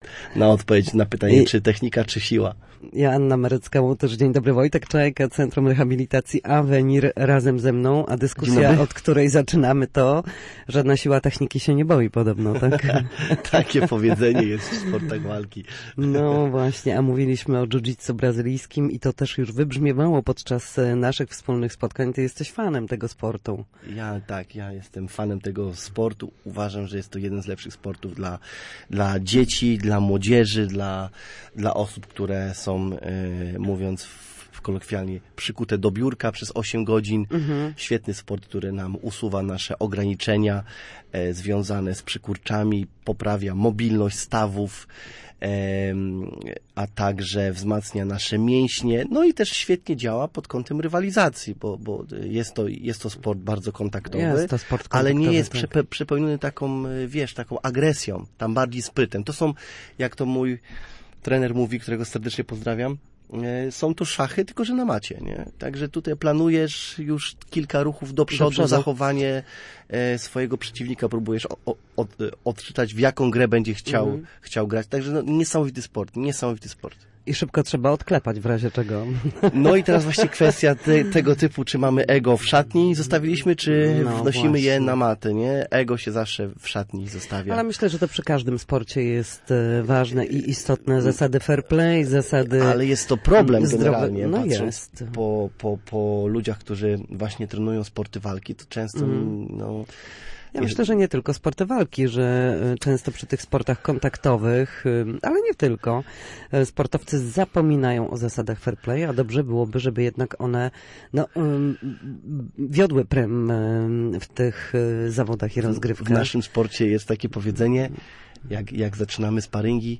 W każdą środę, w popołudniowym Studiu Słupsk Radia Gdańsk, rozmawiamy o tym, jak wrócić do formy po chorobach i urazach.